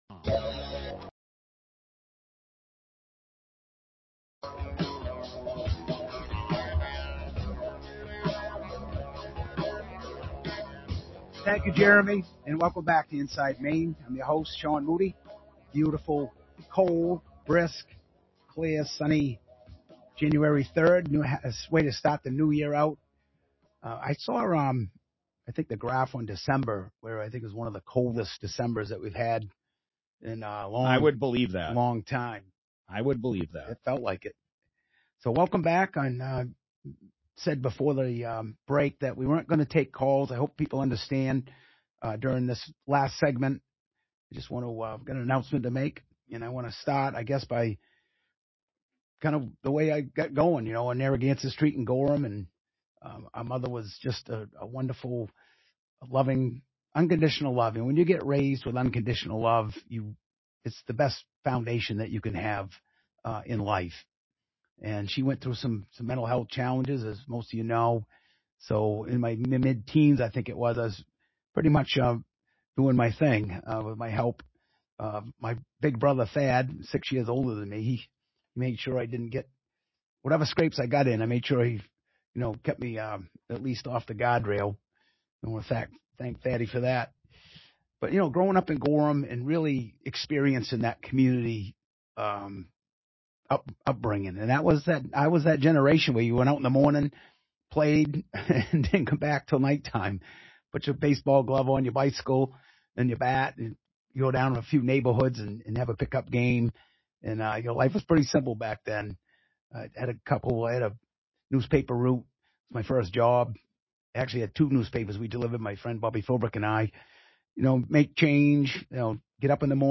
During the final segment on WGAN’s Inside Maine on Saturday